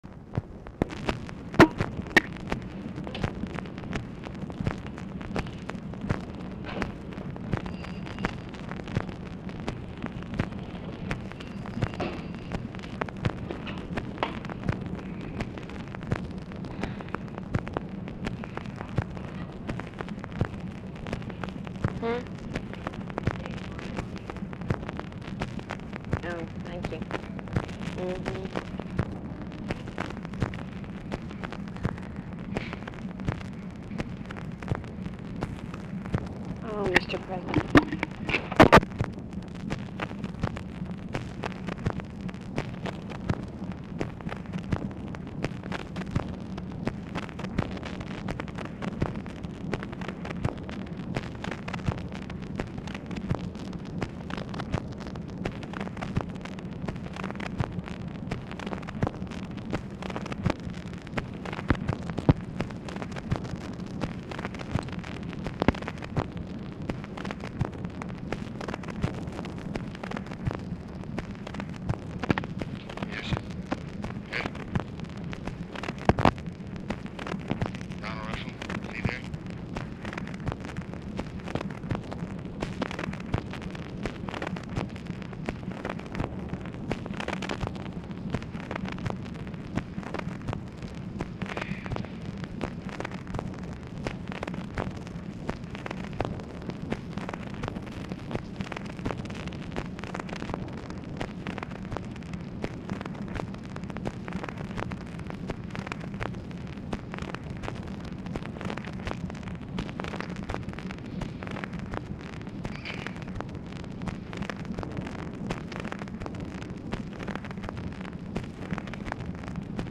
Telephone conversation # 8363, sound recording, LBJ and DONALD RUSSELL, 7/20/1965, 5:34PM
POOR SOUND QUALITY; LBJ AND RUSSELL ARE ALMOST INAUDIBLE
Format Dictation belt
Oval Office or unknown location
OFFICE NOISE, OFFICE CONVERSATION